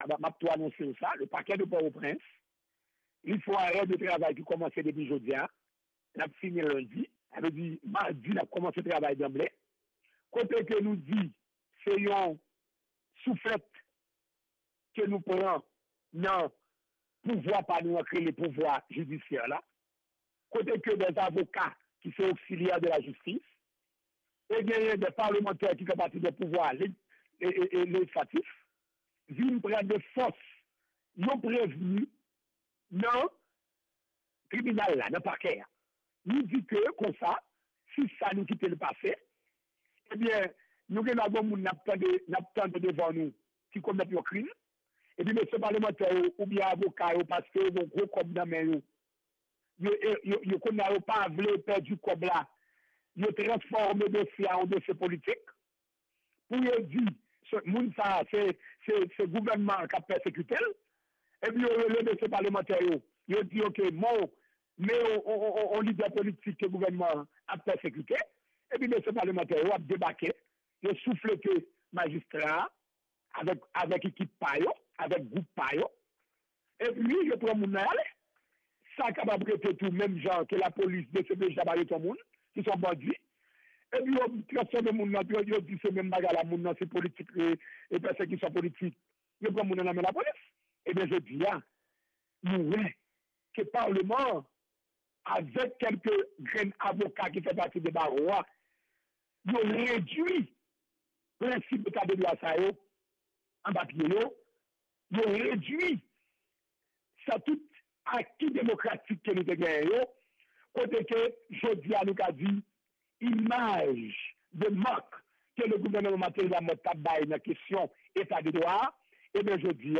Yon repòtaj Lavwadlamerik